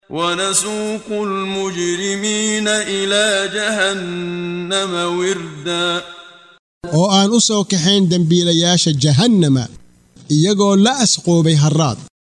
Waa Akhrin Codeed Af Soomaali ah ee Macaanida Suuradda Maryam oo u kala Qaybsan Aayado ahaan ayna la Socoto Akhrinta Qaariga Sheekh Muxammad Siddiiq Al-Manshaawi.